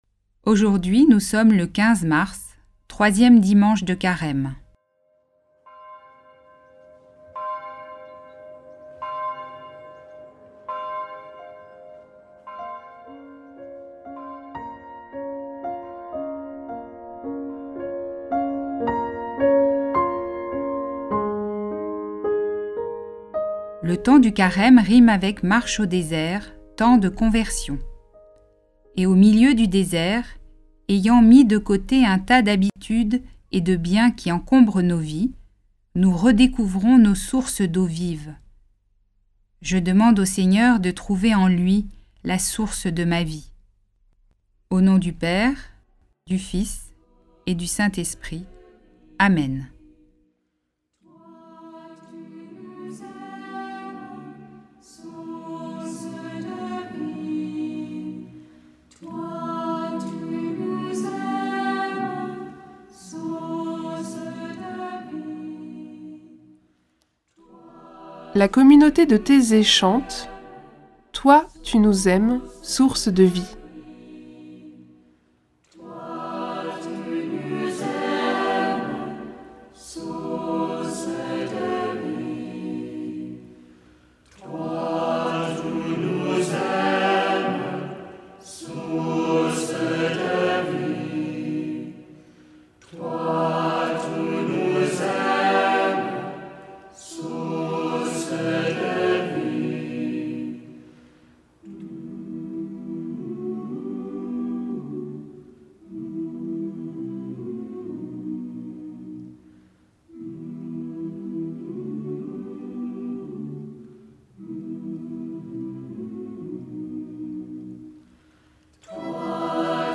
Écouter la méditation et la suivre à son rythme avec les pistes ci-dessous.